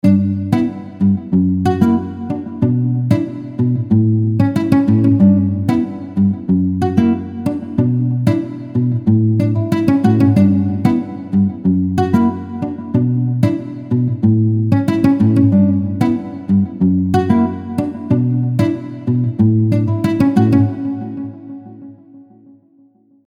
dance_music1.wav